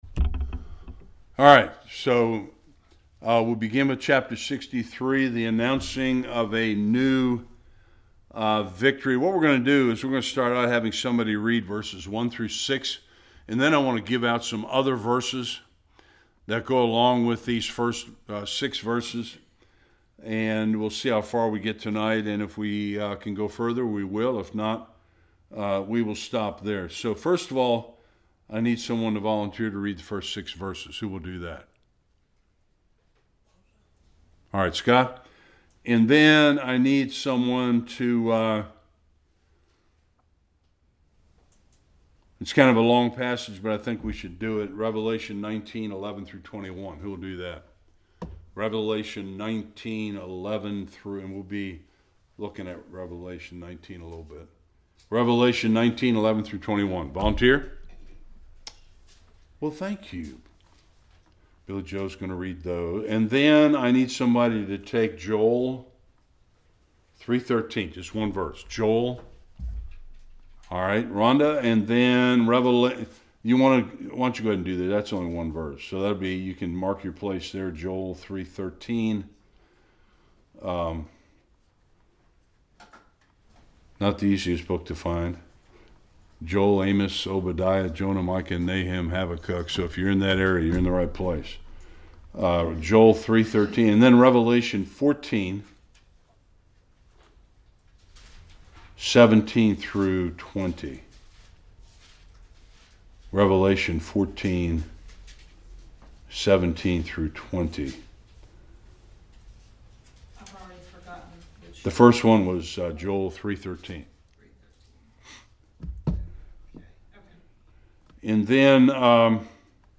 1-6 Service Type: Bible Study A future scene of Christ returning from the battle of Armageddon.